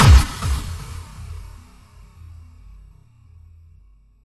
Impact 24.wav